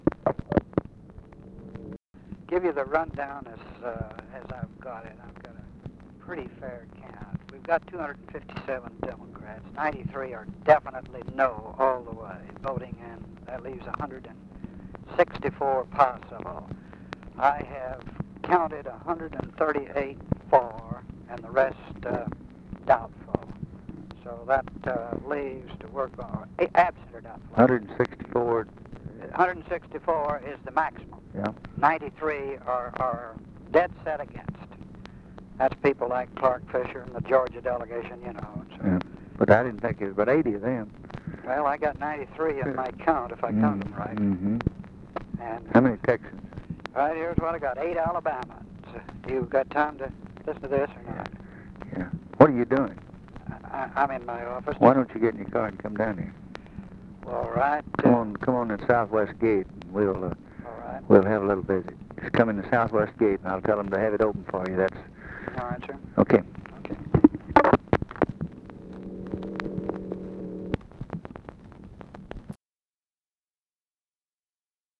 Conversation with CARL ALBERT, December 3, 1963
Secret White House Tapes